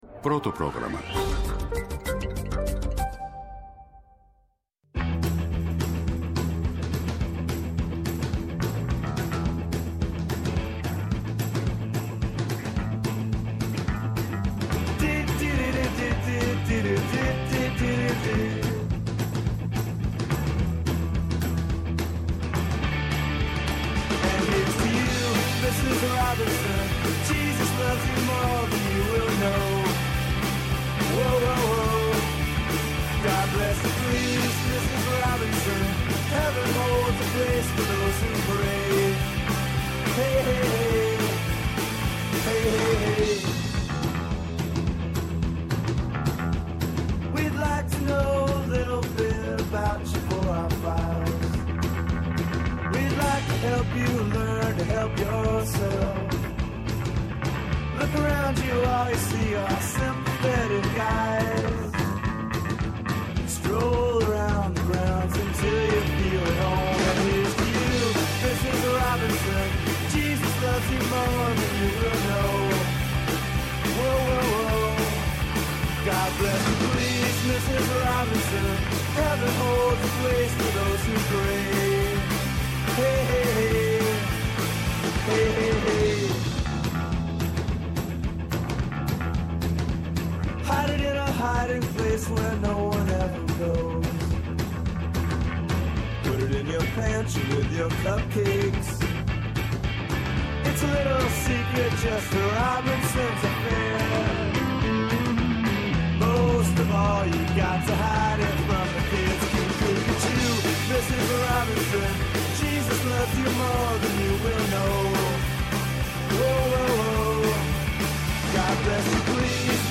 Φοροτεχνικός για την υποχρεωτική ηλεκτρονική καταβολή του ενοικίου